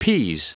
Prononciation du mot pease en anglais (fichier audio)
Prononciation du mot : pease